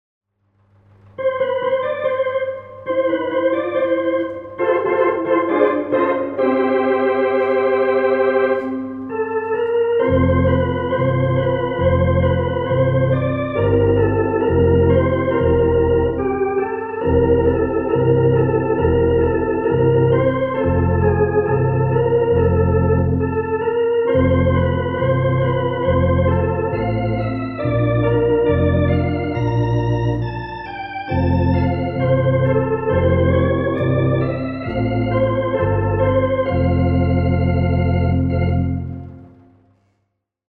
Orgel für Kurt
MERZ Beruhigungsmusik
orgelsolo.mp3